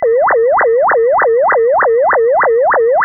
(Fig. 5d): A case where both aliasing of the original signal as well the images has occurred. A discrete-time source where linear interpolation was used and the receiver sampling frequency was 4 kHz.